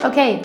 O.K.wav